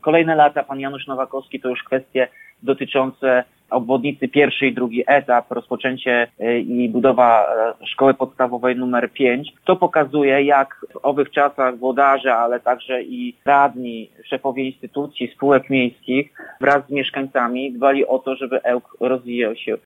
Wspomina prezydent miasta Tomasz Andrukiewicz, który włodarzem jest już od 14 lat.